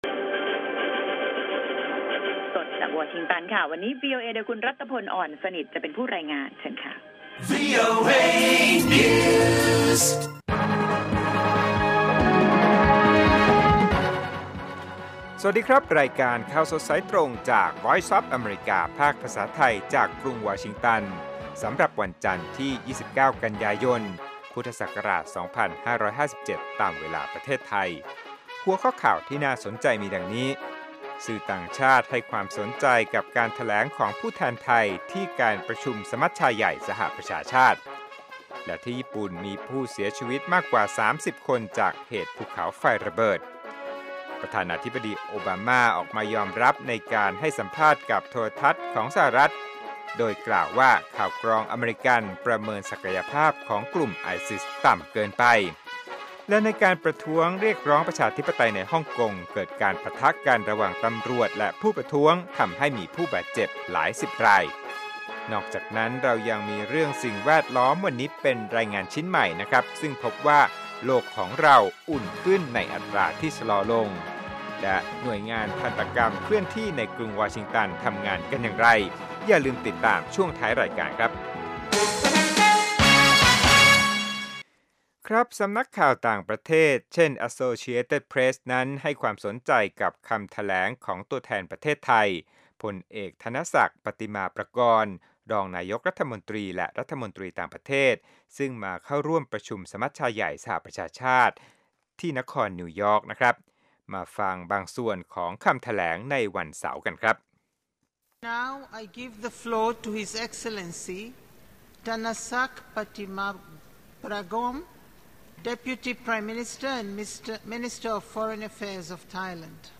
ข่าวสดสายตรงจากวีโอเอ ภาคภาษาไทย 6:30 – 7:00 น.